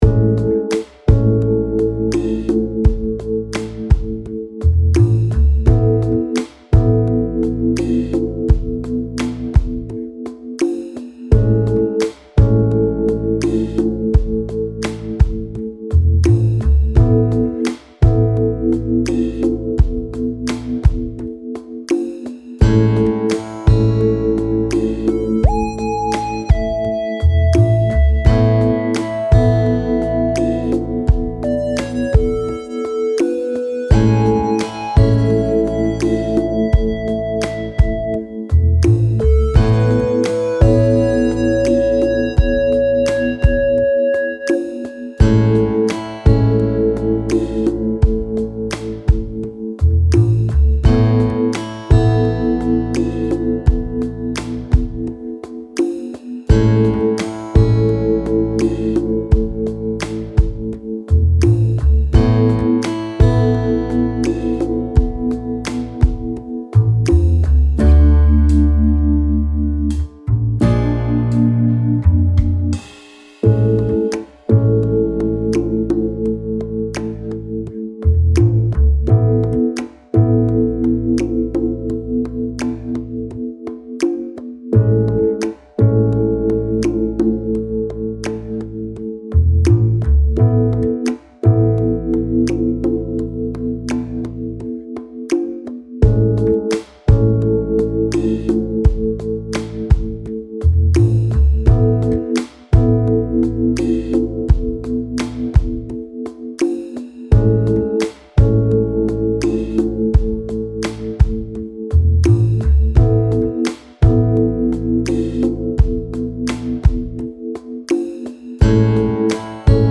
アコースティック 4:49